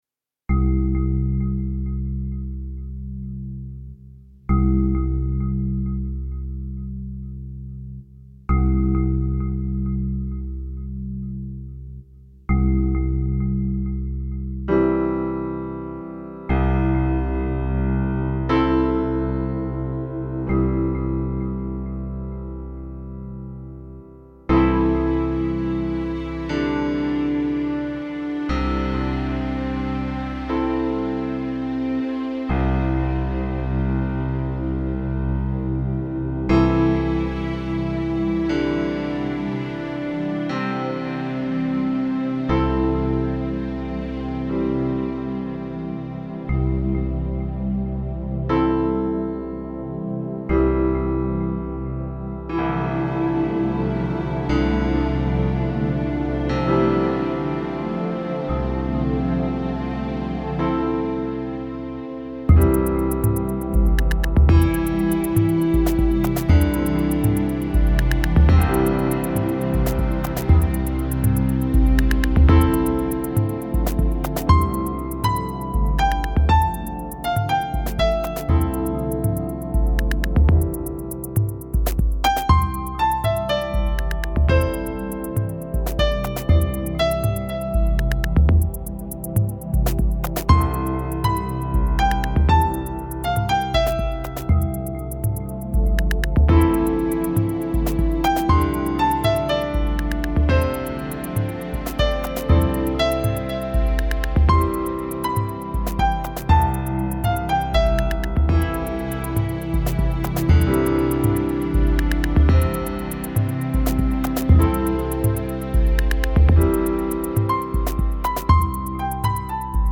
SOUNDTRACKS – INSTRUMENTAL – CHILLOUT